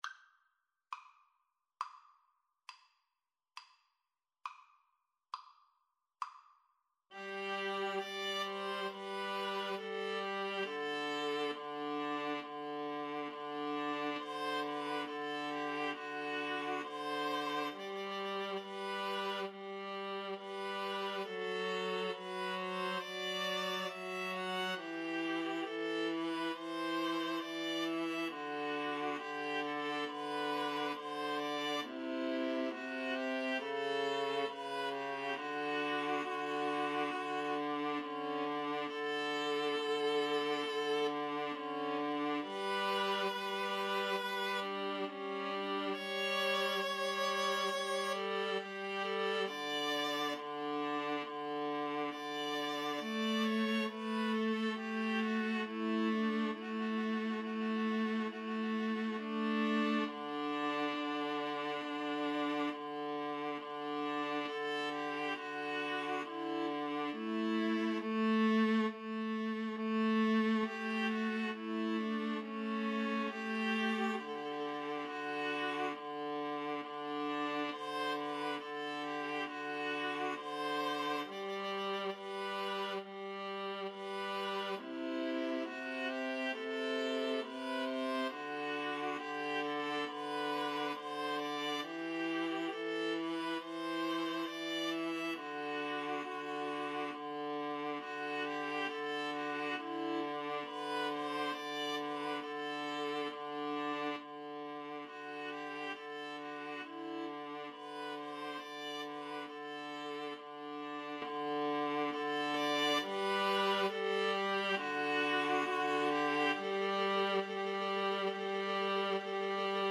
= 34 Grave
4/4 (View more 4/4 Music)
Classical (View more Classical Viola Trio Music)